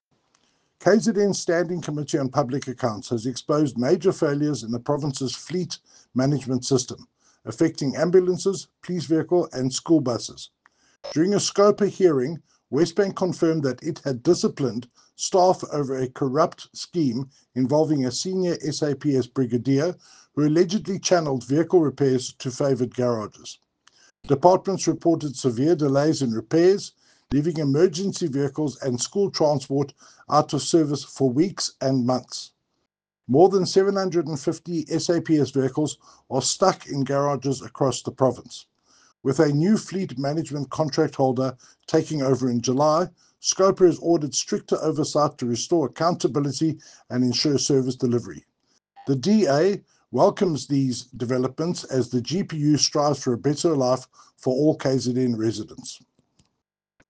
Note to Editors: Please note Tim Brauteseth, MPL sound bite in